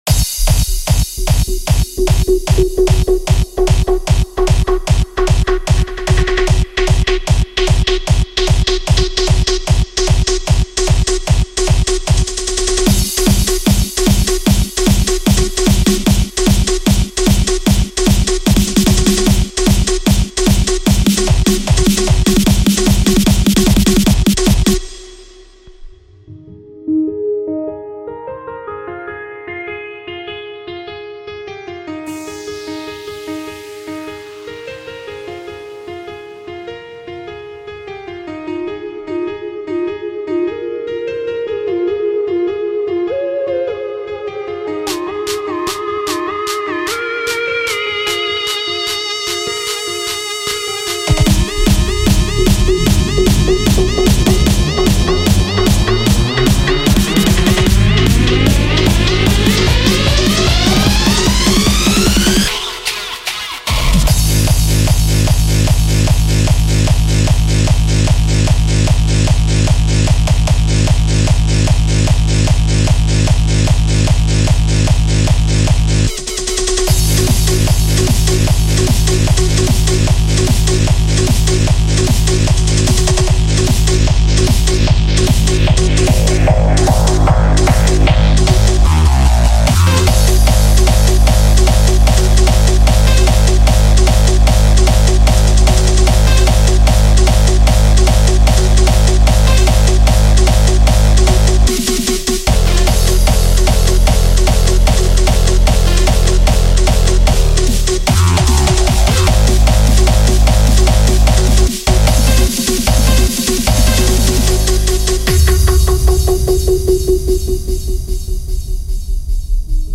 this is the style of the harder ponystyle.